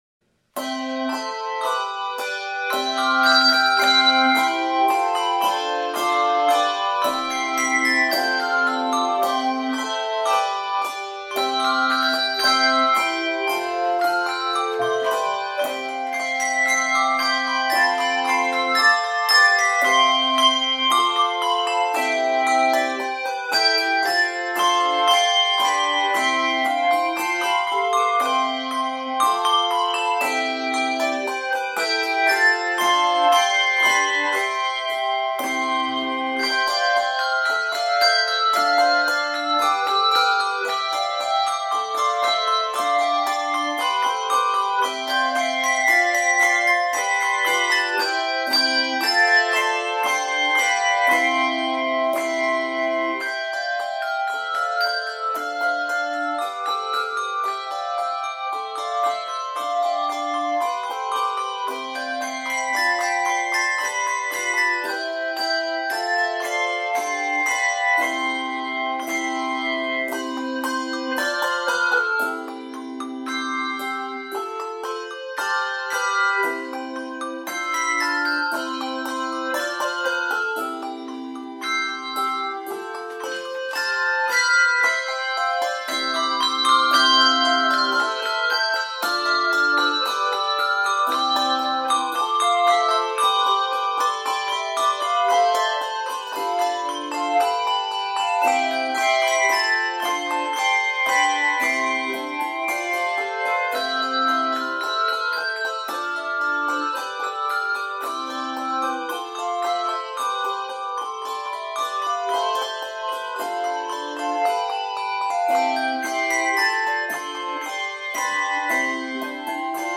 handbell choirs